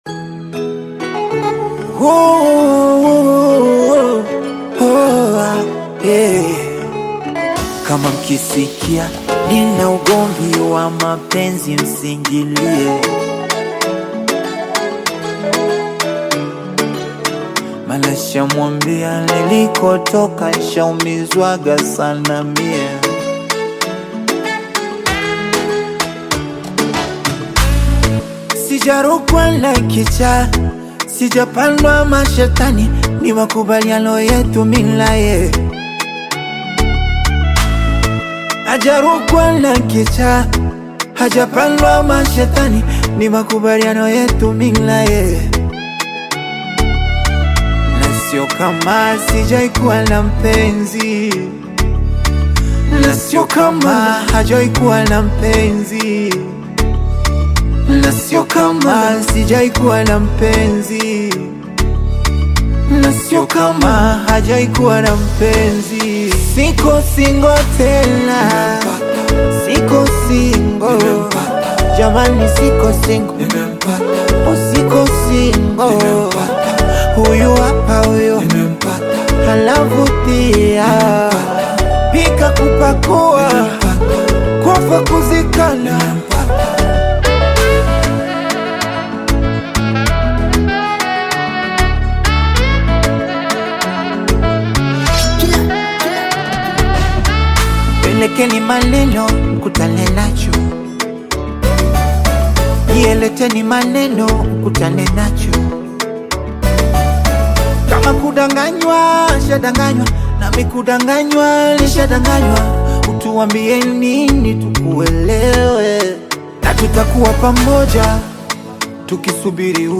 Mp3 Download Tanzanian Bongo Flava artist